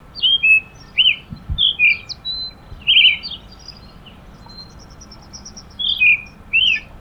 Zorzal – Universidad Católica de Temuco
Zorzal-Turdus-falcklandii.wav